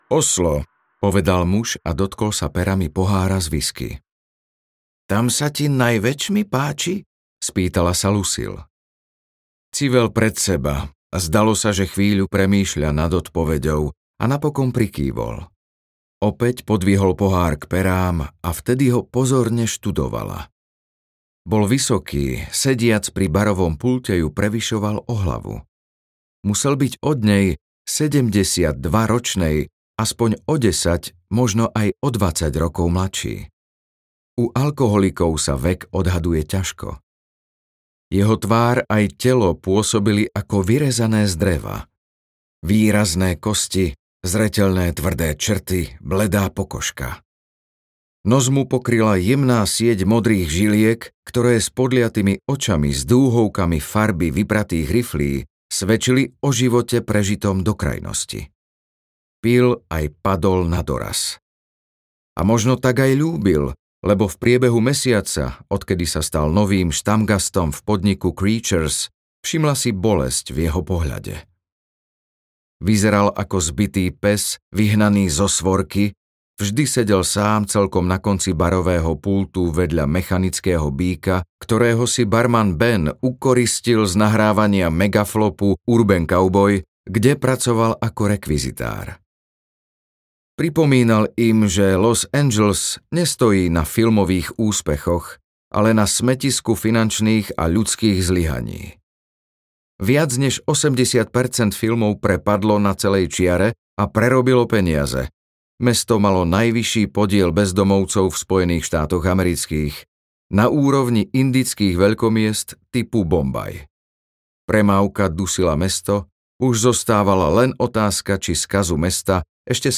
Krvavý mesiac audiokniha
Ukázka z knihy